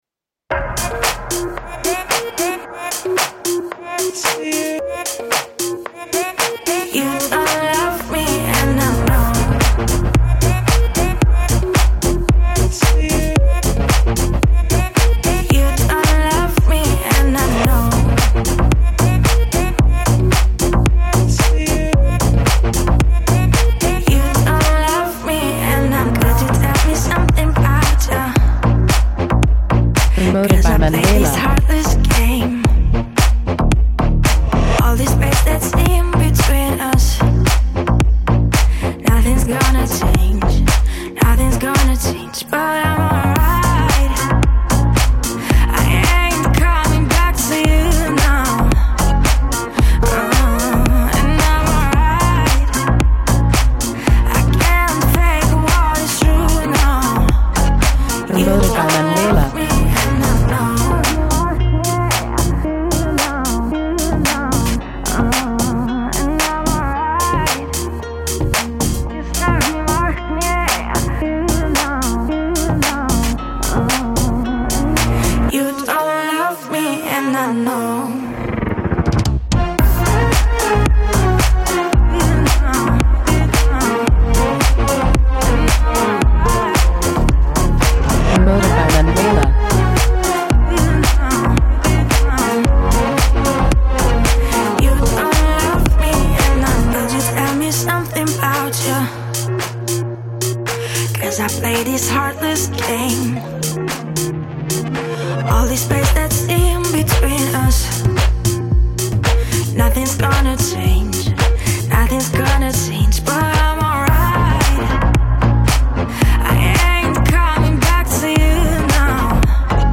Extended Club Edit